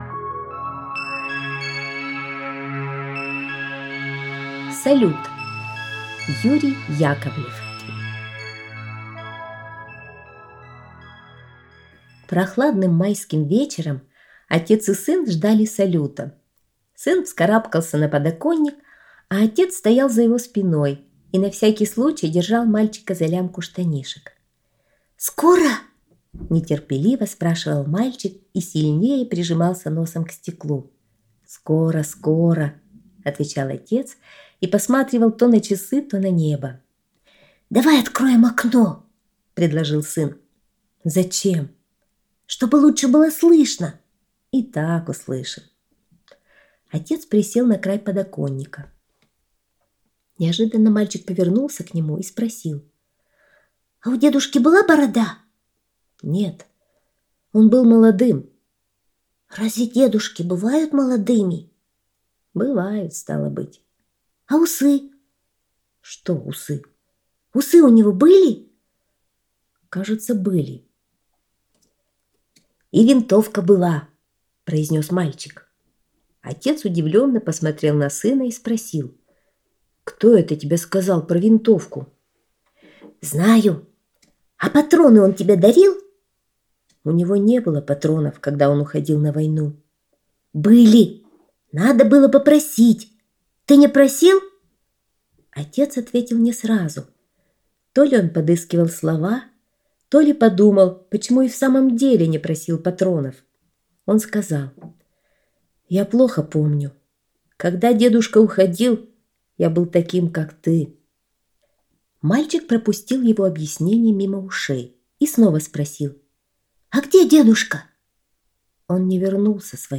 Аудиорассказ «Салют»